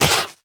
biter-roar-5.ogg